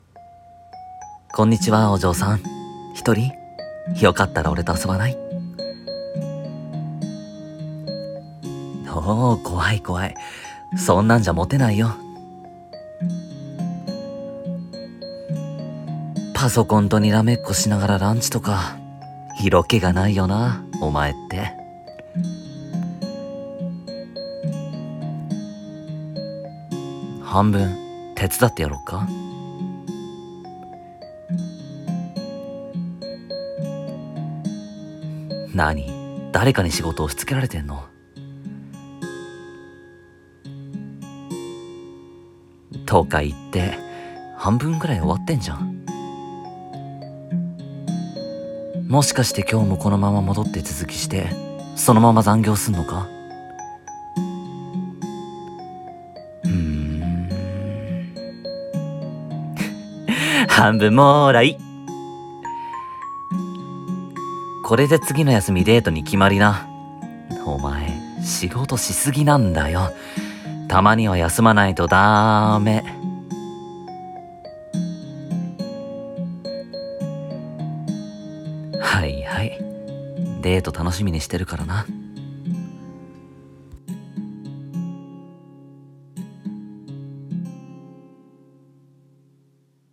【二人声劇】強がりには勝手を【台本】